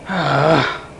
Frustrated Sound Effect
Download a high-quality frustrated sound effect.
frustrated-1.mp3